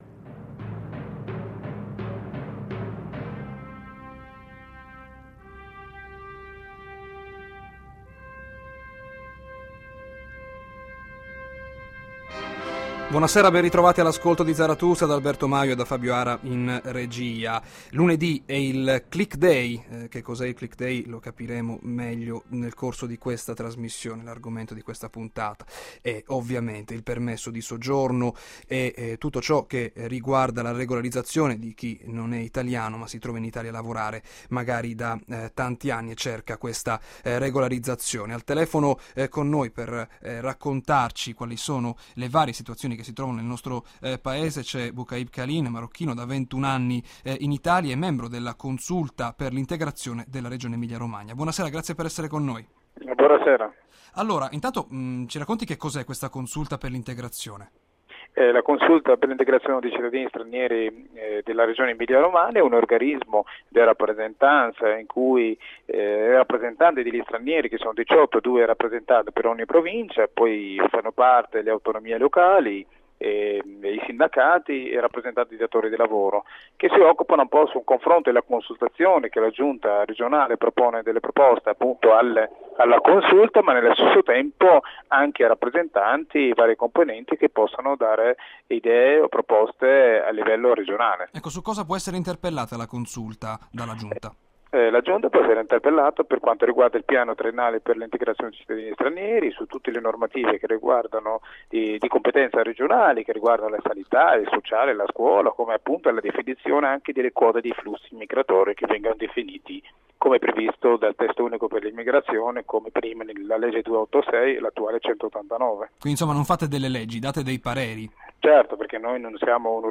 Ascolta la puntata andata in onda sabato 29 gennaio, alle 18,05, su Radio Italia anni '60 - Emilia Romagna.